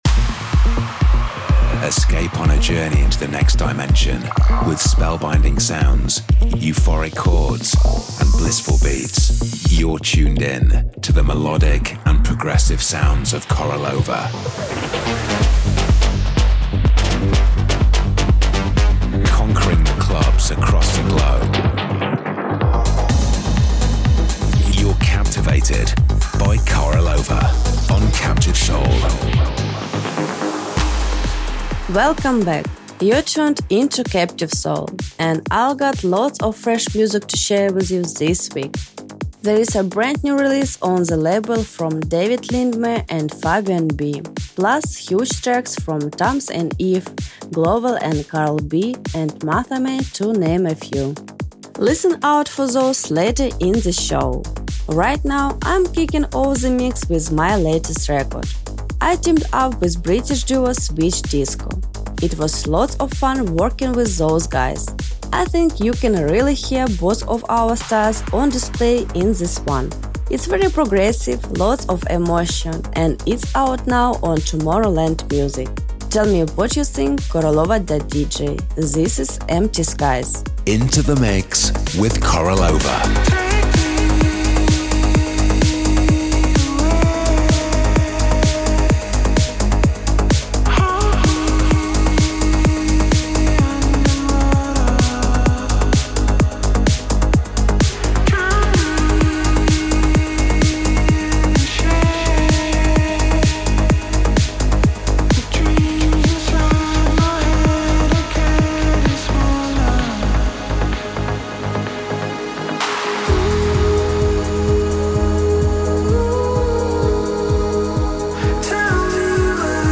music DJ Mix in MP3 format
Genre: Progressive house; Duration